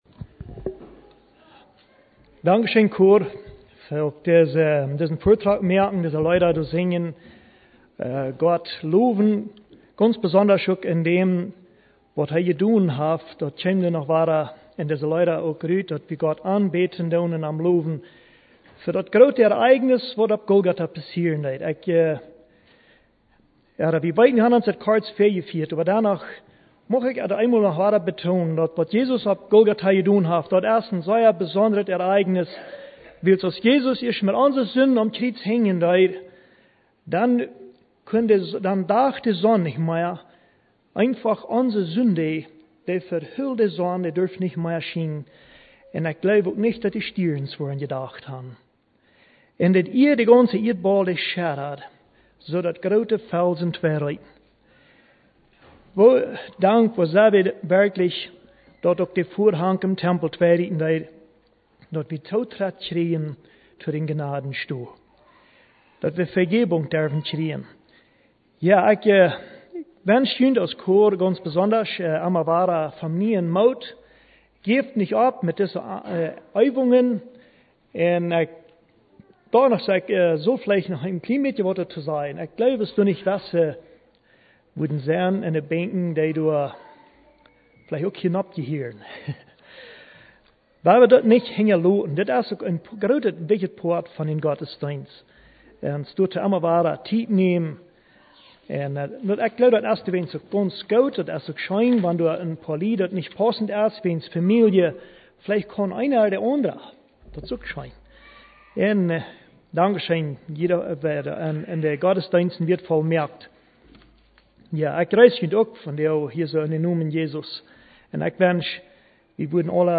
Pred.